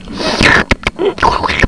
suhlurp.mp3